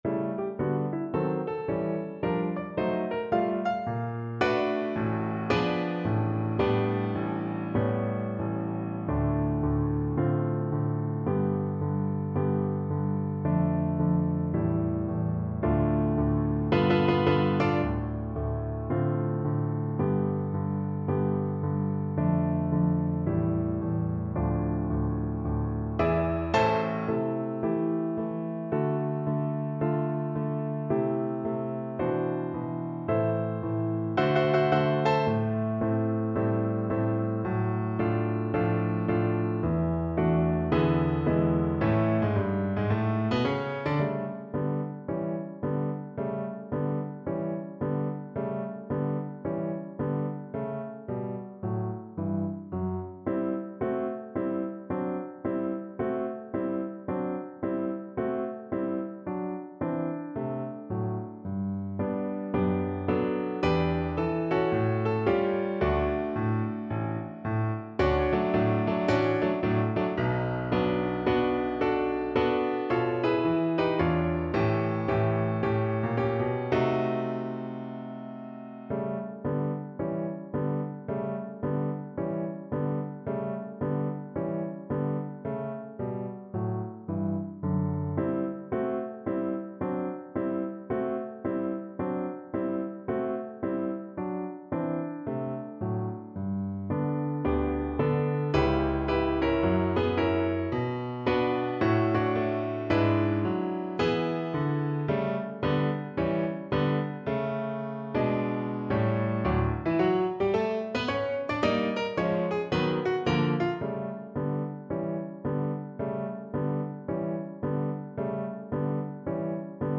Moderato =110 swung
4/4 (View more 4/4 Music)
Classical (View more Classical Cello Music)